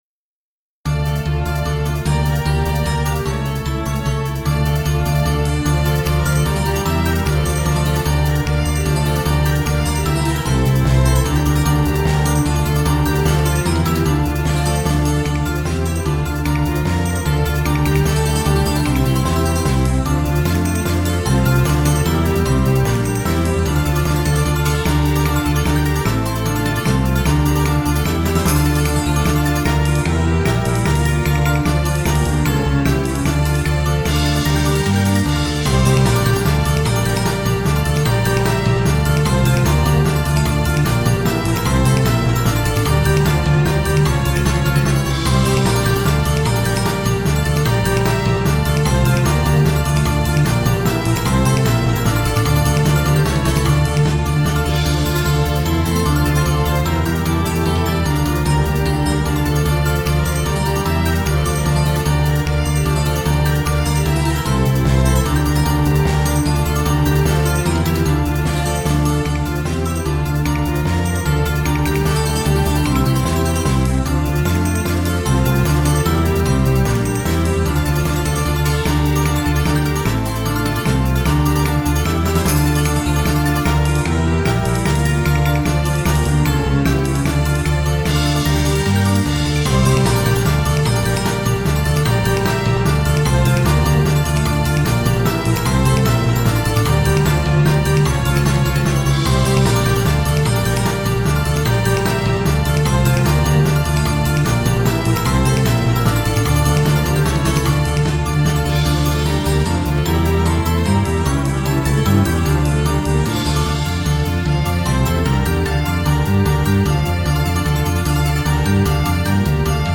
〜オフボーカル版〜